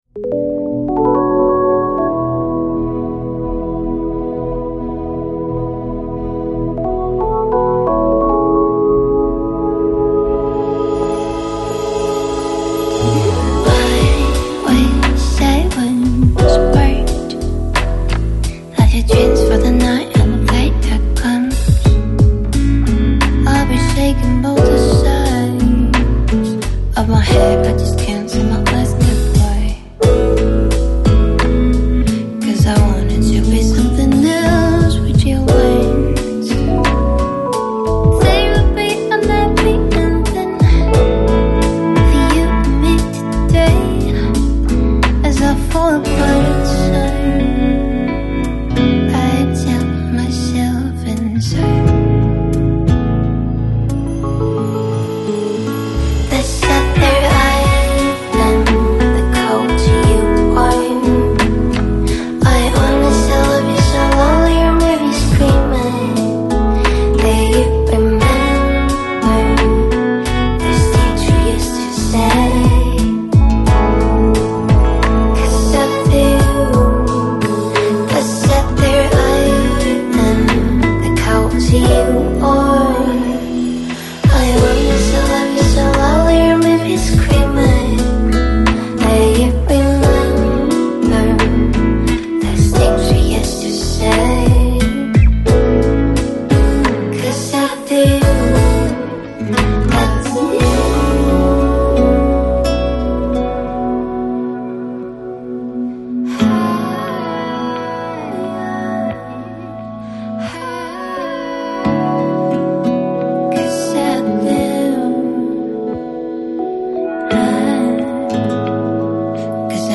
Жанр: Lo-Fi, Lounge, Chillout, Vocal